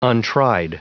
Prononciation du mot untried en anglais (fichier audio)
Prononciation du mot : untried